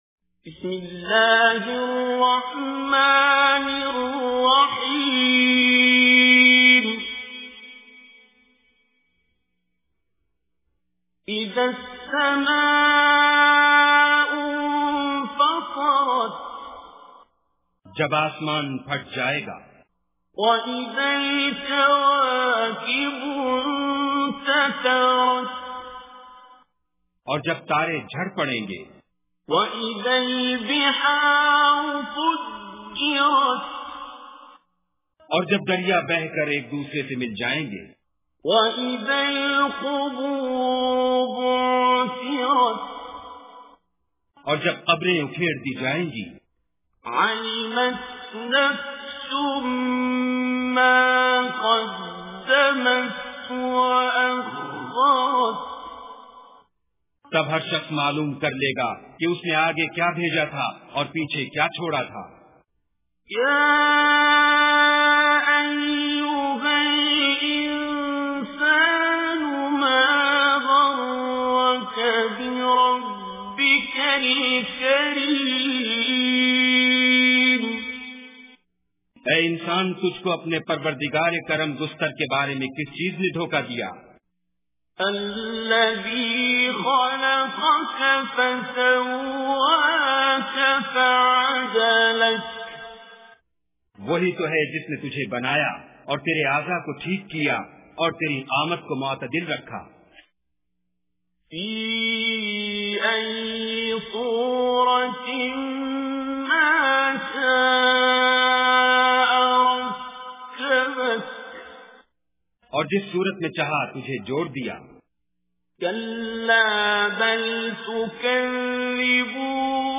Surah Infitar Recitation with Urdu Translation
Surah Infitar is 82nd chapter of Holy Quran. Listen online and download mp3 tilawat / recitation of Surah Infitar in the beautiful voice of Qari Abdul Basit As Samad.